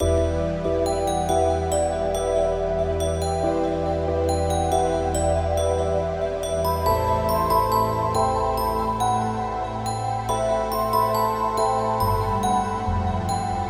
幽灵般的呻吟声
描述：24位48kHz的wav文件录音，由一男一女五个音轨混合在一起，没有任何处理。
标签： 万圣节 哀号 可怕 呻吟
声道立体声